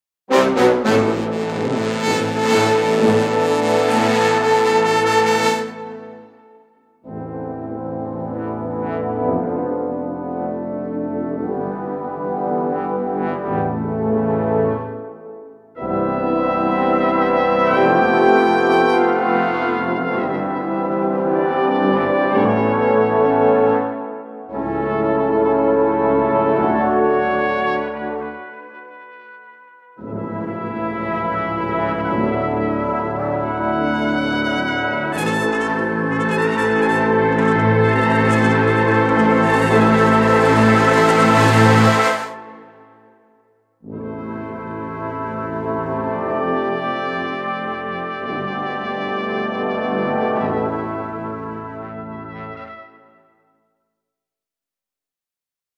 其他技巧包括各种长短音、颤音、气音、滑音等。